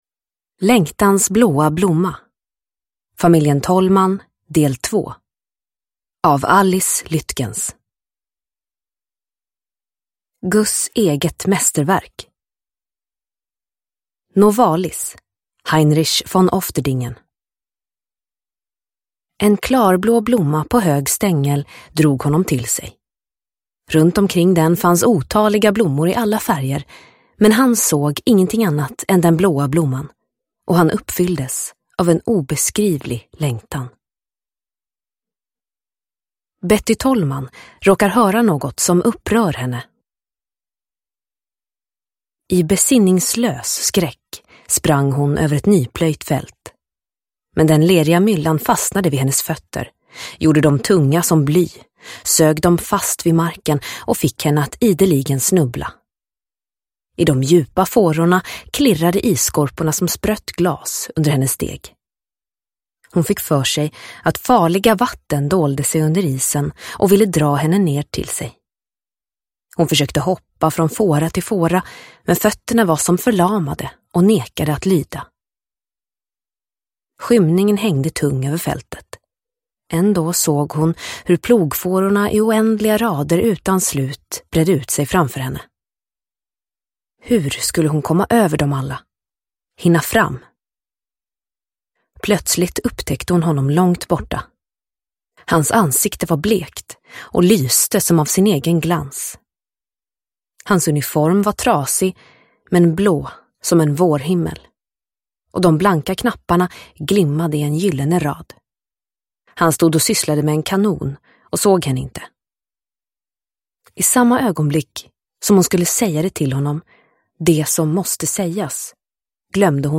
Längtans blåa blomma – Ljudbok – Laddas ner